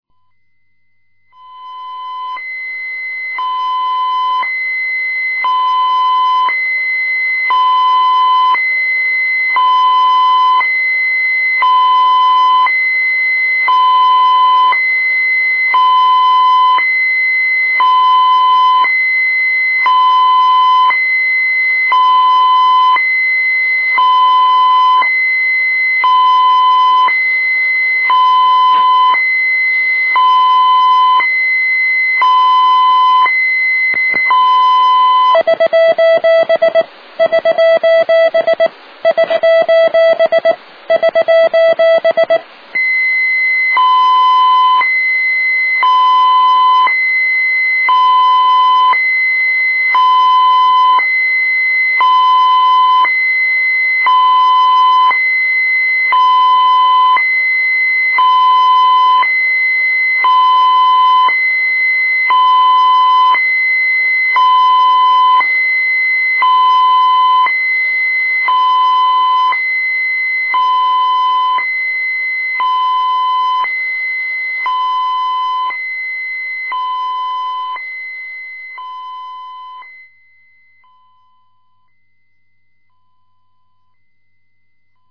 Arduinoで小惑星探査機「はやぶさ 」のビーコン音を再現しました。
4. 2048Hzと1024Hzの音響信号を1秒おきに繰り返すビーコン音が聞こえる。
本プログラムをArduino上で実行し，その放送電波？をAMラジオで受信してその音を録音しました。
はやぶさビーコン音 mp3版( 170214 byte,11025Hzサンプリング)
実は，起動時に，５回モールス信号の"SOS"を送出し，その後，ビーコンモードに移行ようにプログラムしている。
HayabusaBeacon.mp3